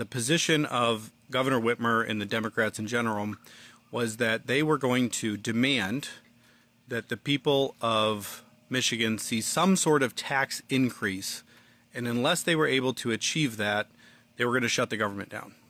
State Senator Jonathon Lindsey from Coldwater says in a Facebook video one of the sticking points has been  proposed tax increases  by the Governor and state democrats